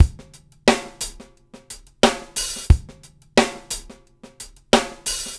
SMP DRMDRY-R.wav